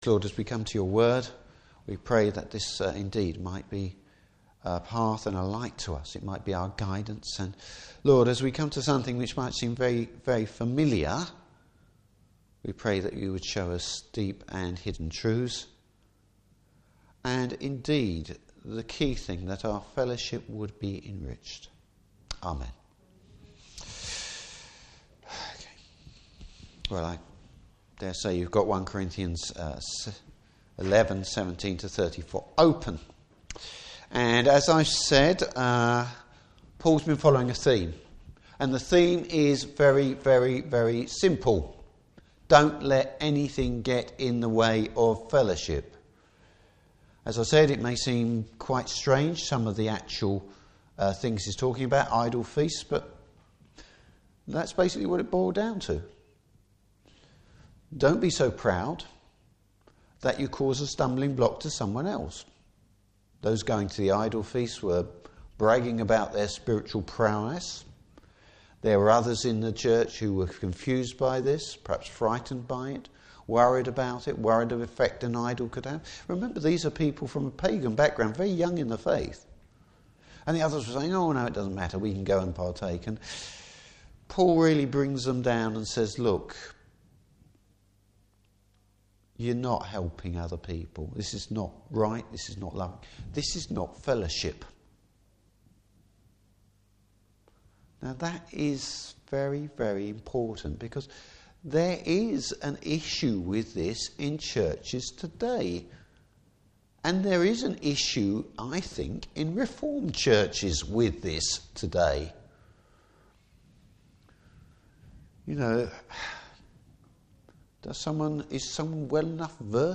Service Type: Morning Service What it means to partake of the Lord’s Table.